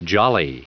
Prononciation du mot jolly en anglais (fichier audio)
Prononciation du mot : jolly